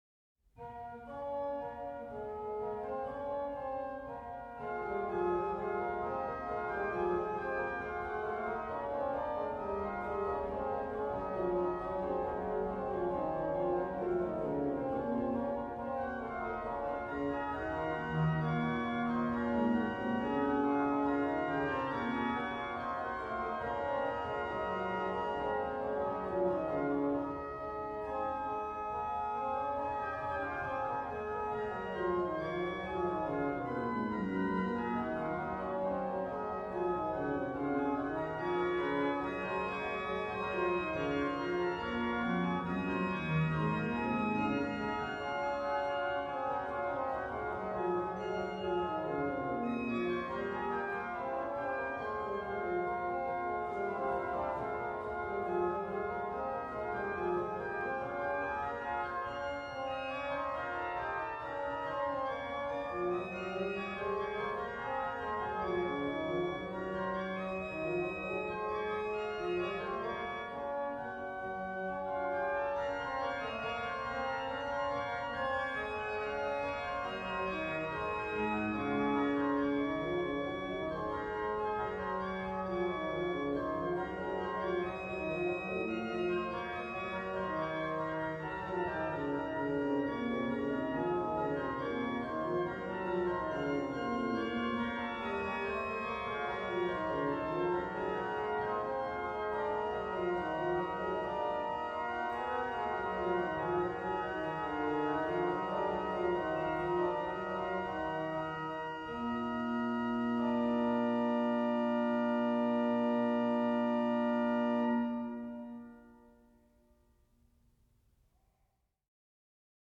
Subtitle   manualiter
Registration   Pos: Qnt8, Oct4